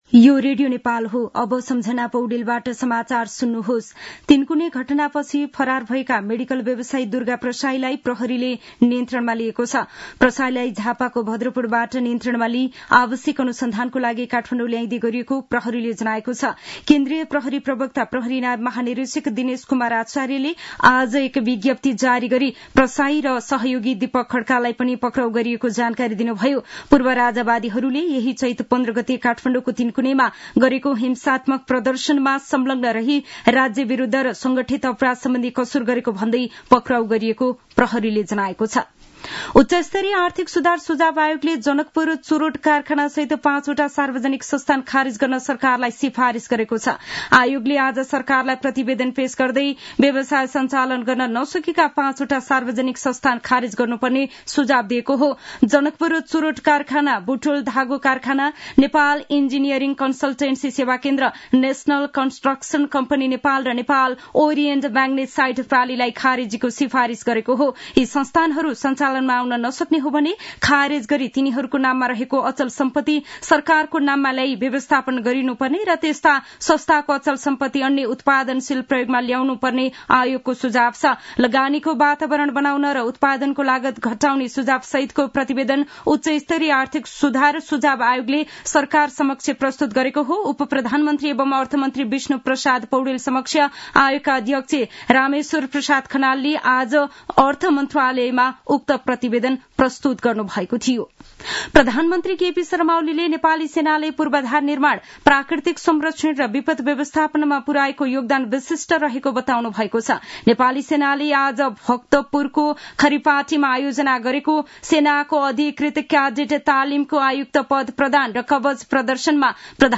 दिउँसो १ बजेको नेपाली समाचार : २९ चैत , २०८१